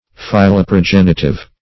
Search Result for " philoprogenitive" : The Collaborative International Dictionary of English v.0.48: Philoprogenitive \Phil`o*pro*gen"i*tive\, a. Having the love of offspring; fond of children.
philoprogenitive.mp3